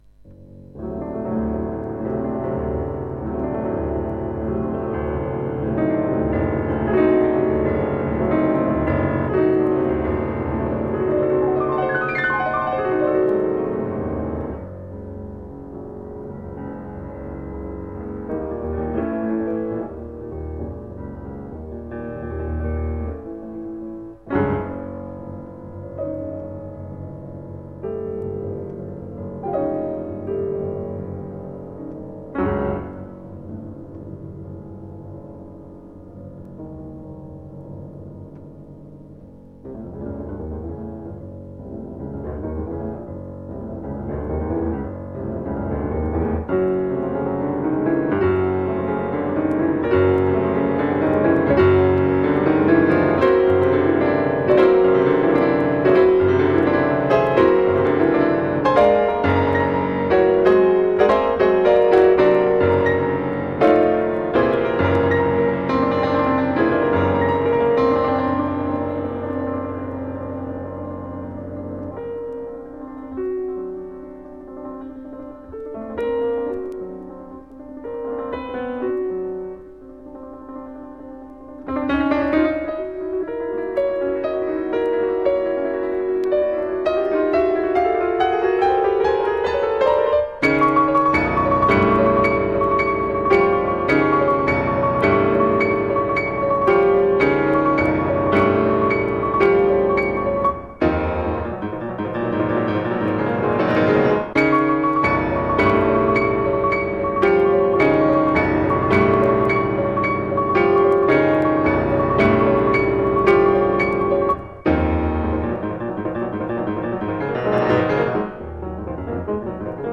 Musique : " CE QUE LE VENT D'OUEST A VU " de Claude Debussy interprété par Thérèse Dussaut avec son aimable autorisation.